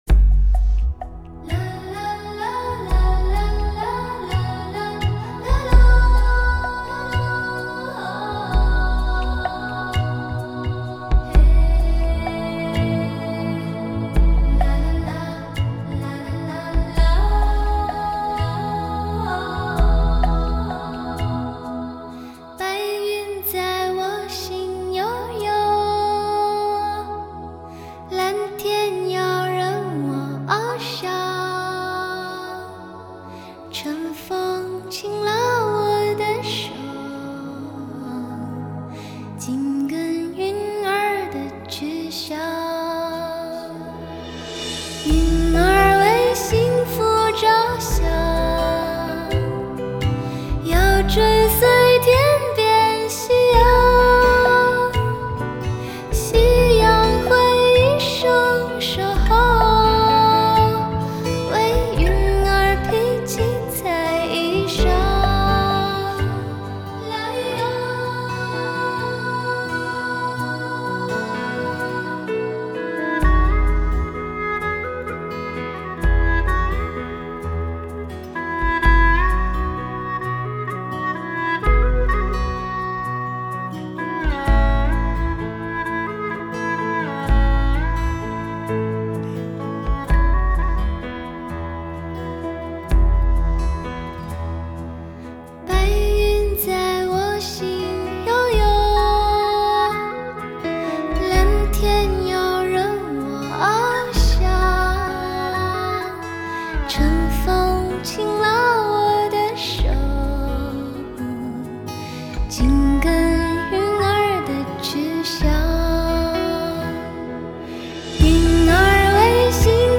从天而降的天籁之音，以空灵的悸动触碰你灵魂深处最清澈的温柔......